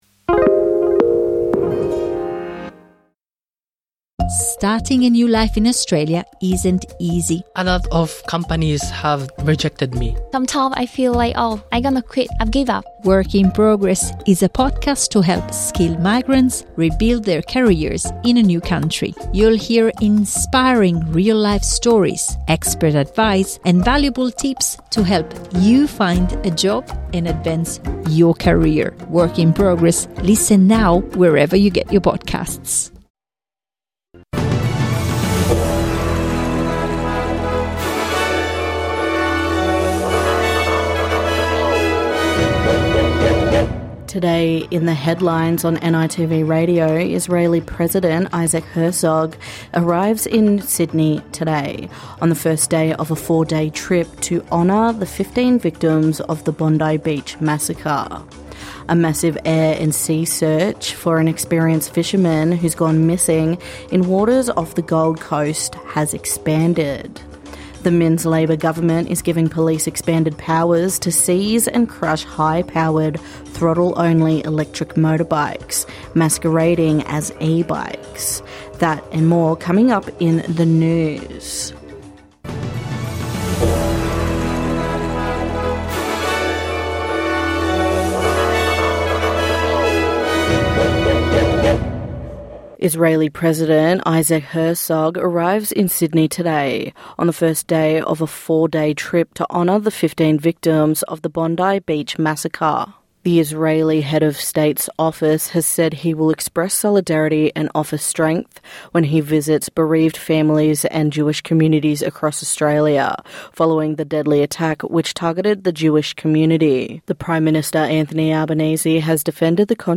NITV Radio bringing the latest in news sport and weather as well as stories from across the country and in community.